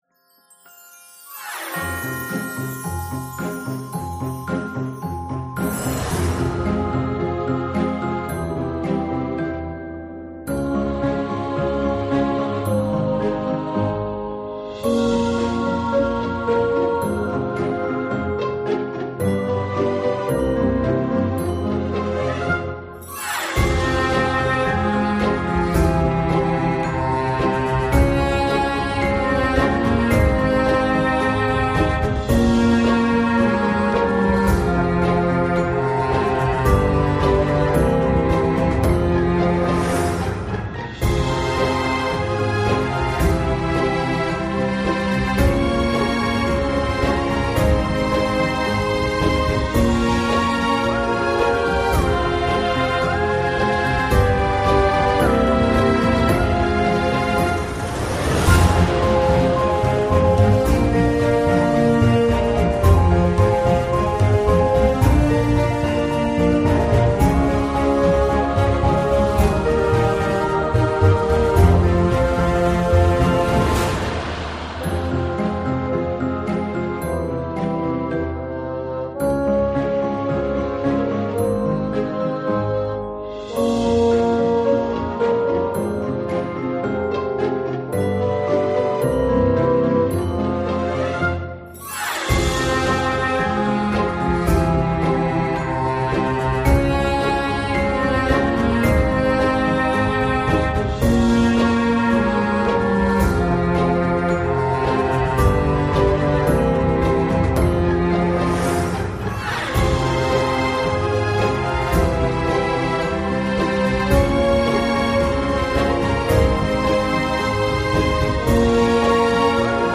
For an immersive soundtrack, click the ► button:
Magic-and-Fantasy-AudioTrimmerco.mp3